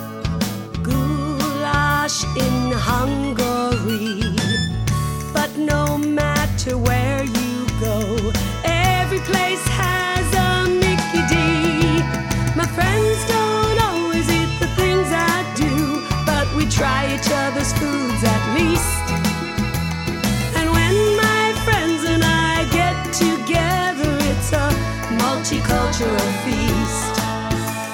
Genre: Childrens.